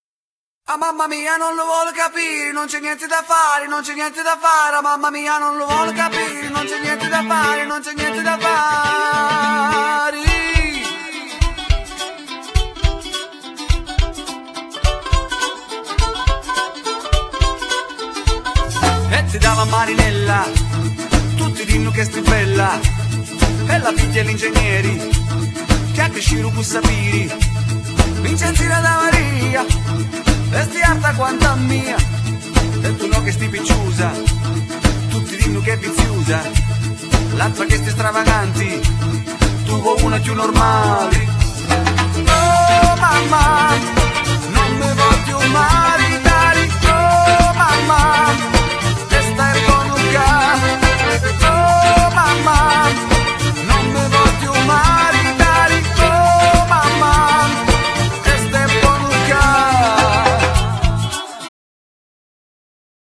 Genere : Pop / Etno